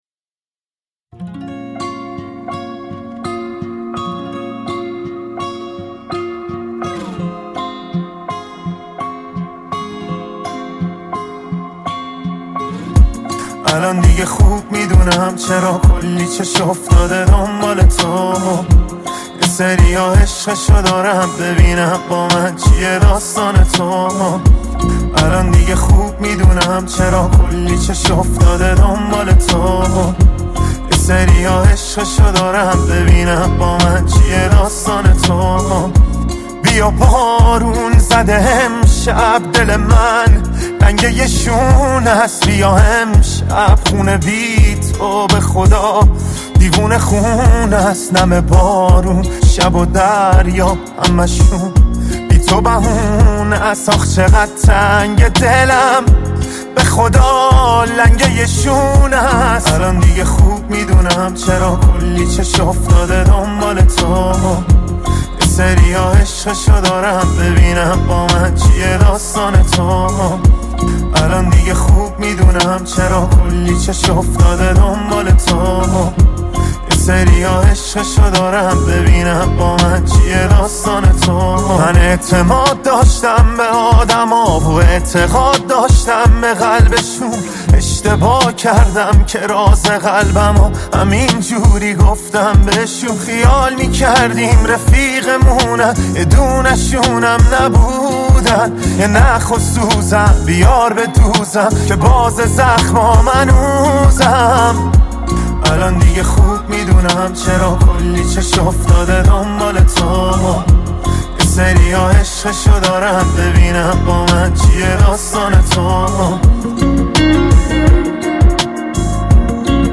غمگین
عاشقانه و غمگین